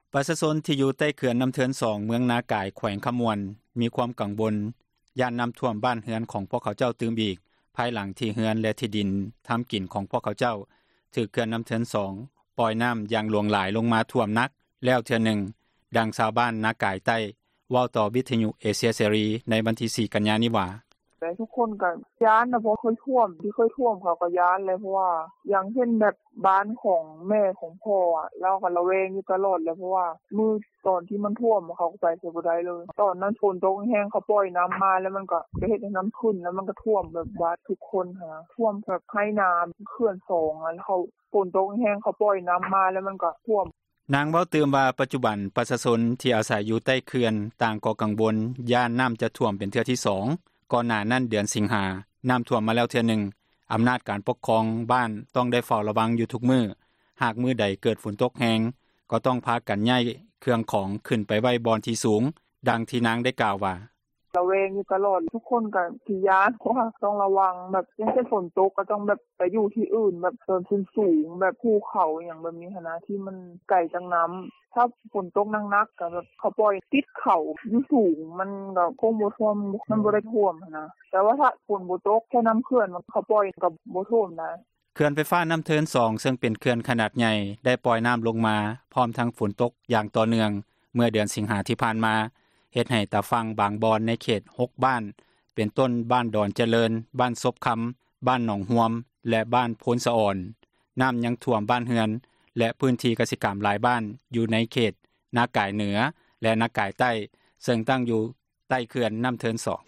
ດັ່ງຊາວບ້ານ ບ້ານນາກາຍໃຕ້ ເວົ້າຕໍ່ວິທຍຸເອເຊັຍເສຣີ ໃນມື້ວັນທີ 4 ກັນຍາ ນີ້ວ່າ: